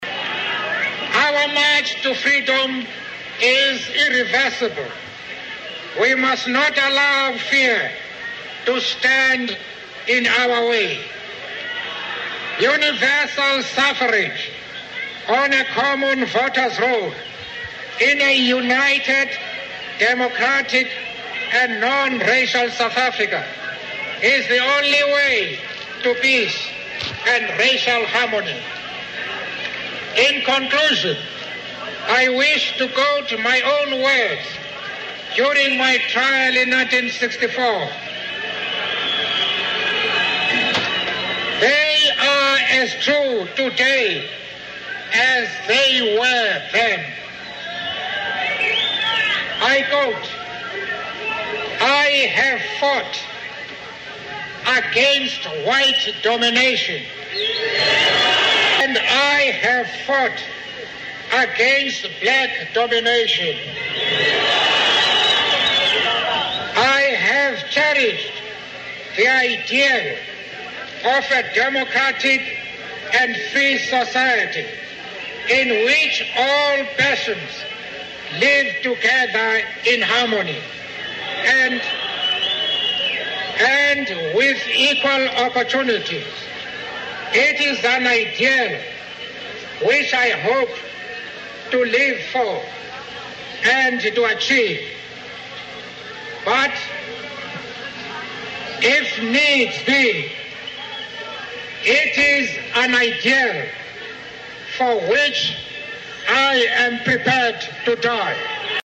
名人励志英语演讲 第39期:为理想我愿献出生命(13) 听力文件下载—在线英语听力室